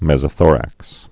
(mĕzə-thôrăks, mĕs-)